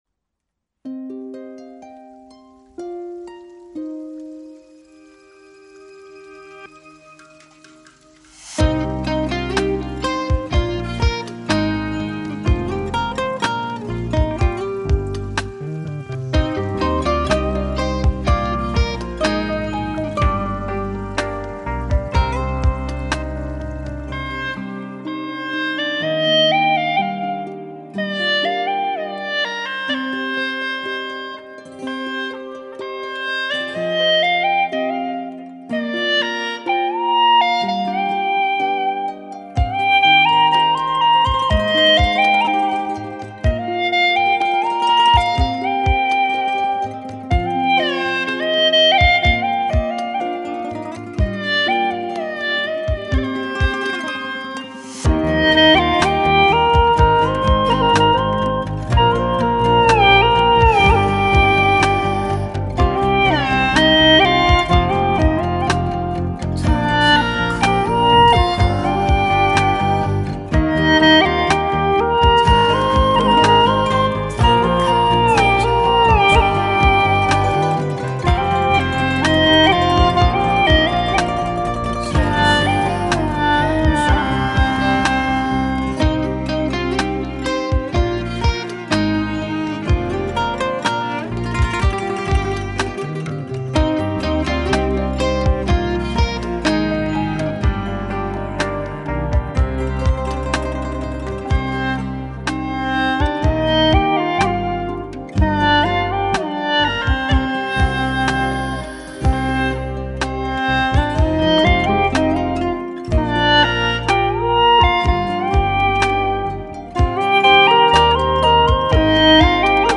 曲类 : 流行